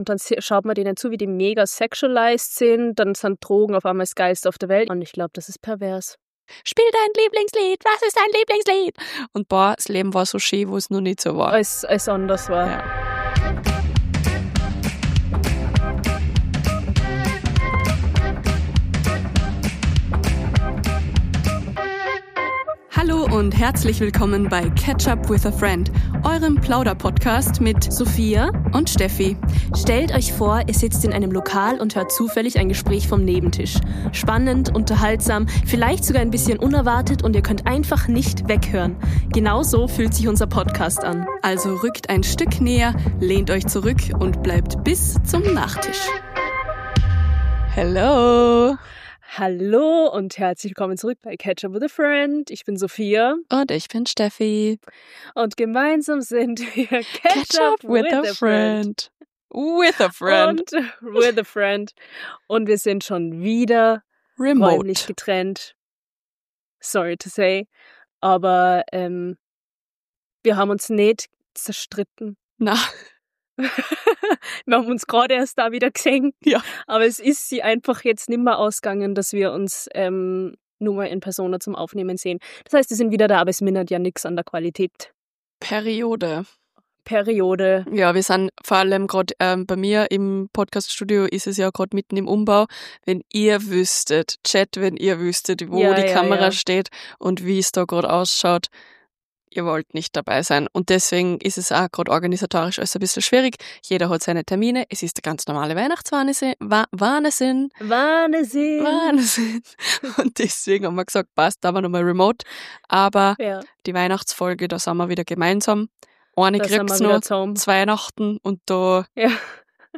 In dieser Remote-Folge plaudern wir über einen Abend zwischen Store-Event und nobler Afterparty im Palais.